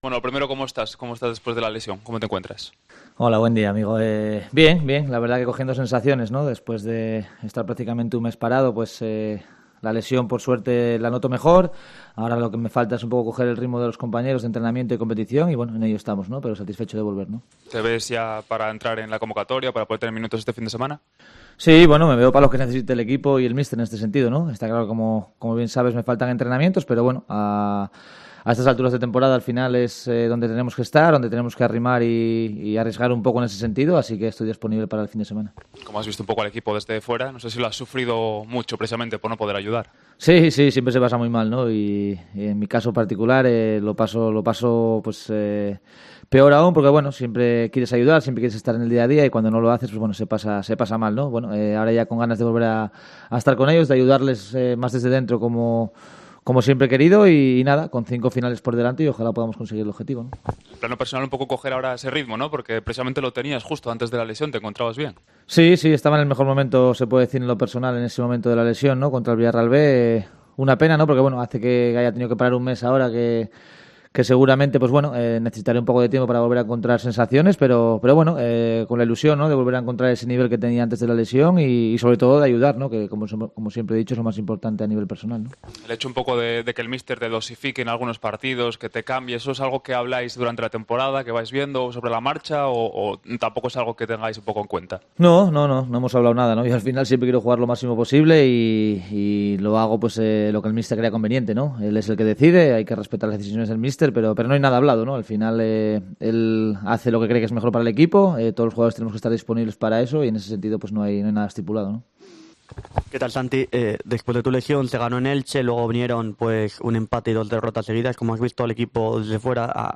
El 'mago' ha comparecido ante los medios de comunicación en la sala de prensa de El Requexón.